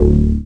cch_bass_one_shot_dark_D.wav